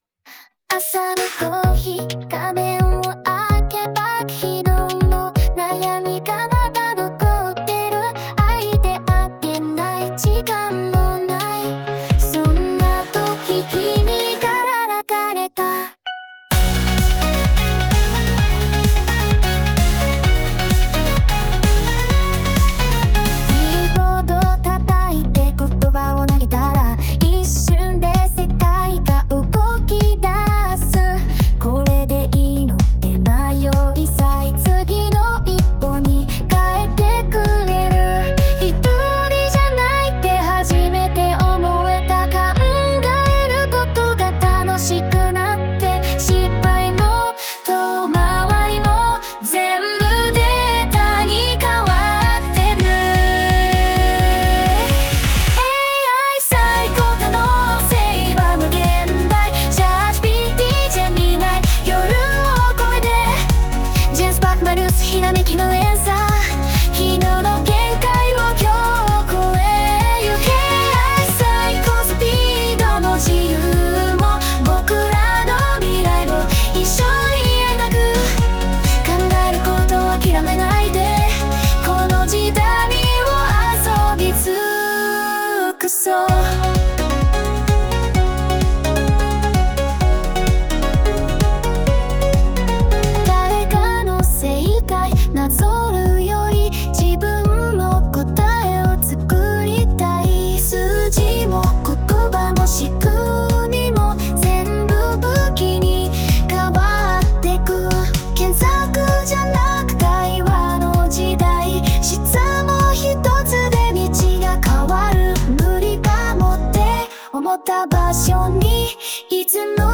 実際にGensparkのAIで作成した音楽が以下になります。